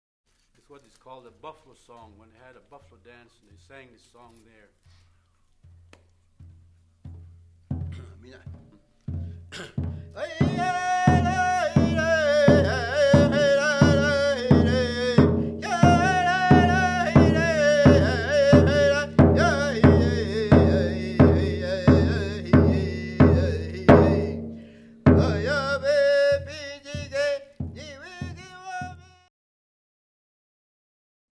This song has been sung in the past as a ceremonial song.